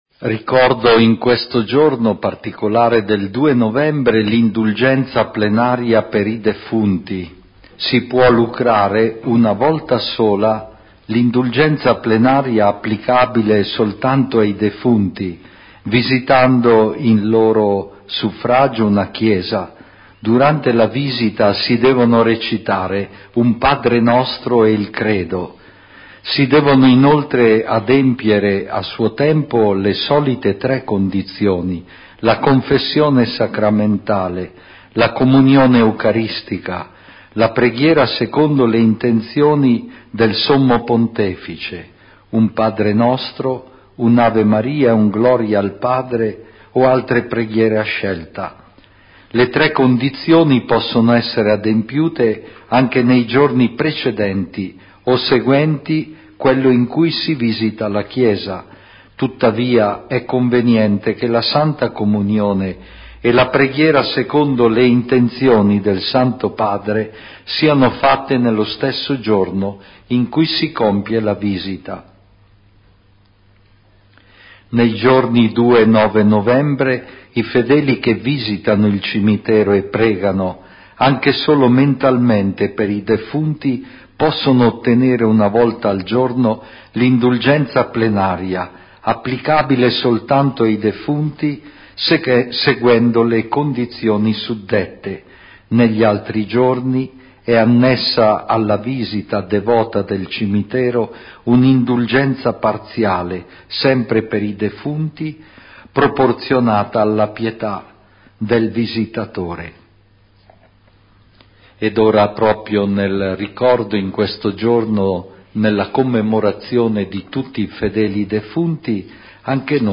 Dalla Cappellina di maria: serata mariana eucaristica